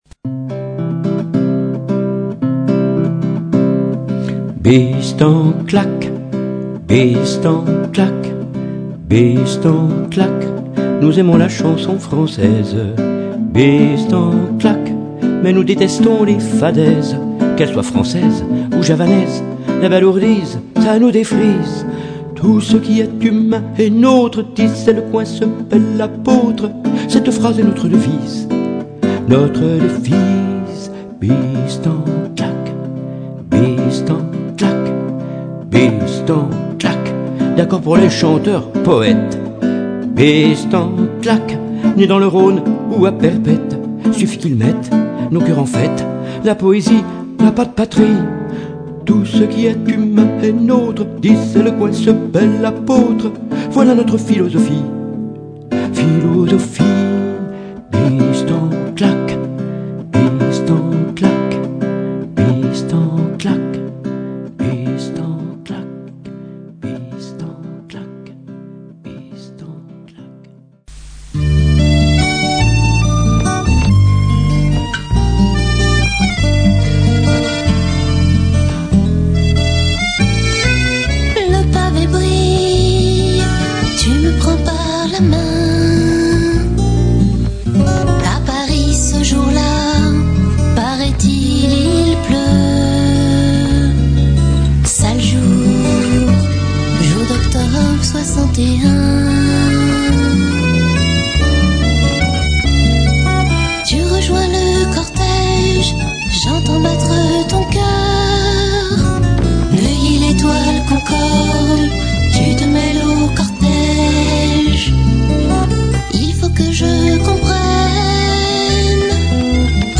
par la radiodiffusion d’ une création radiophonique théâtrale inédite par la compagnie PARLONS EN de la pièce